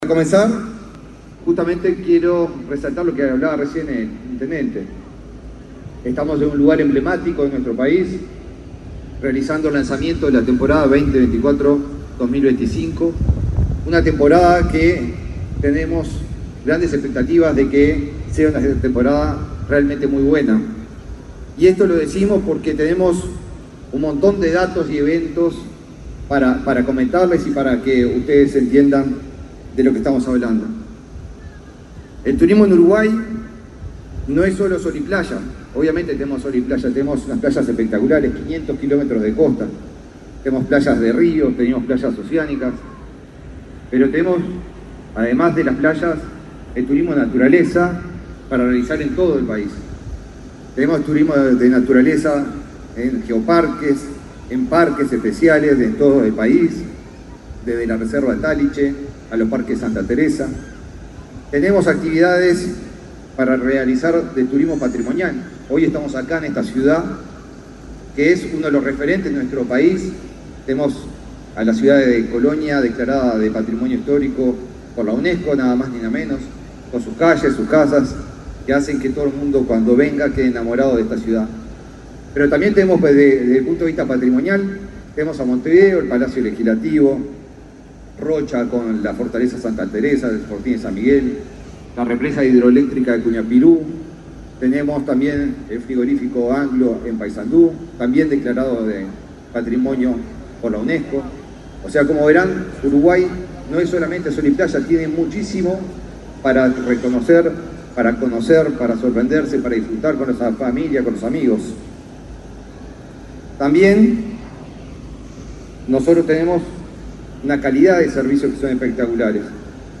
Palabras del ministro de Turismo, Eduardo Sanguinetti
Palabras del ministro de Turismo, Eduardo Sanguinetti 16/11/2024 Compartir Facebook X Copiar enlace WhatsApp LinkedIn El ministro de Turismo, Eduardo Sanguinetti, participó, este sábado 16, en el lanzamiento de temporada turística, en la Plaza de Toros Real de San Carlos, en Colonia del Sacramento, departamento de Colonia.